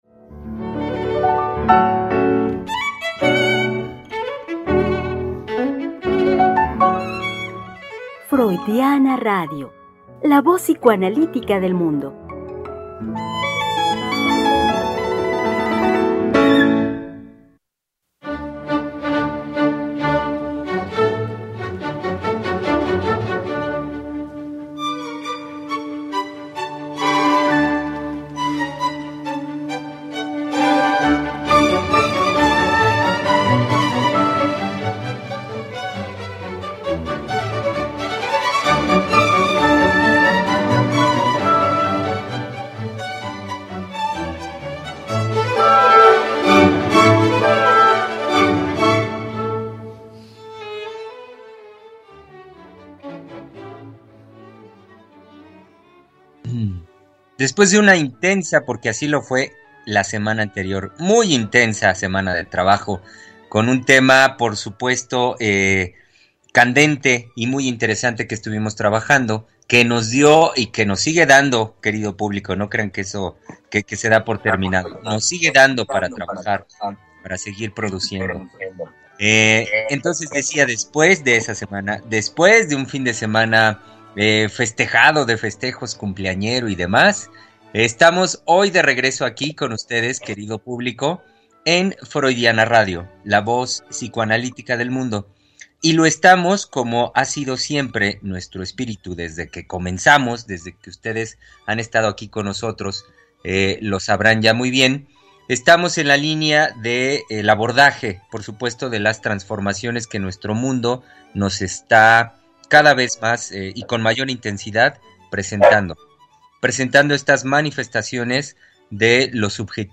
Tres Mujeres Psicoanalistas Hablando de la Vida Cotidiana.
Conversación